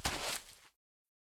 Minecraft Version Minecraft Version snapshot Latest Release | Latest Snapshot snapshot / assets / minecraft / sounds / mob / turtle / walk1.ogg Compare With Compare With Latest Release | Latest Snapshot
walk1.ogg